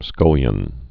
(skŭlyən)